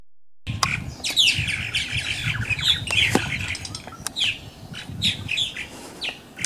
purple-martins.mp3